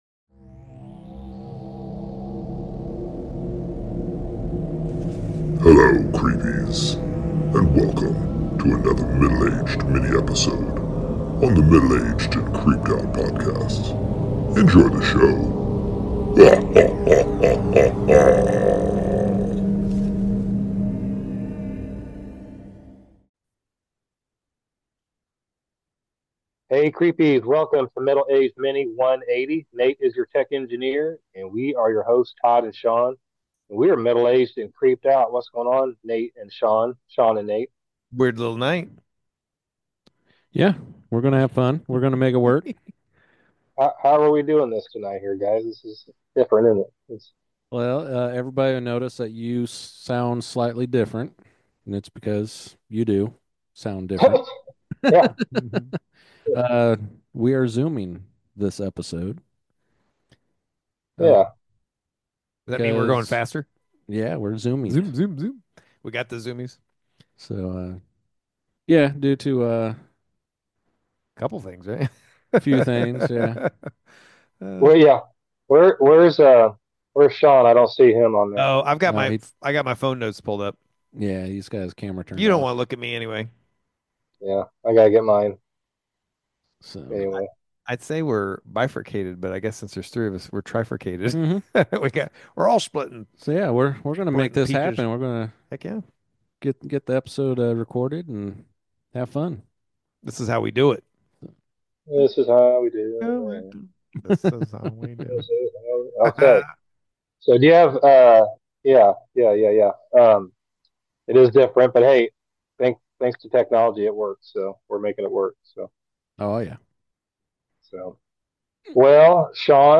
The guys have a fun but creepy "little" discussion on the mythical creature...El Duende!!!